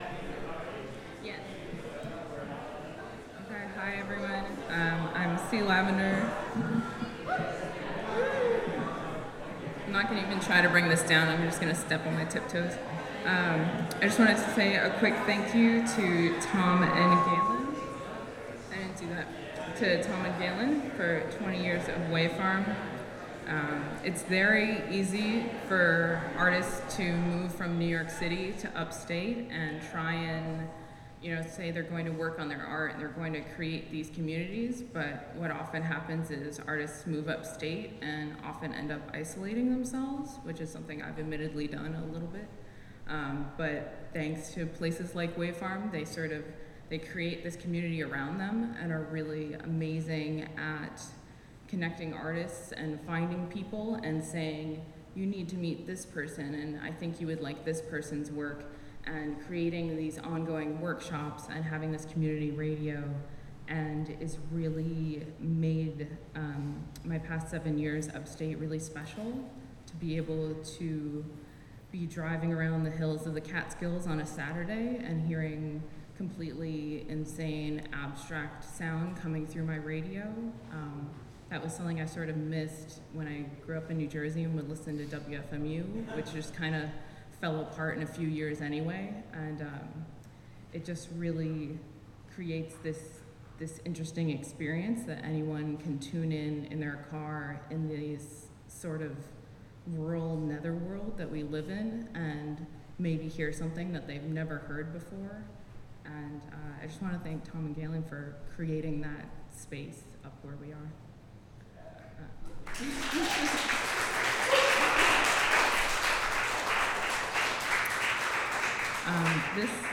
performance during Wave Farm 1997-2017 at Fridman Gallery, NYC.
This durational celebration features live performa...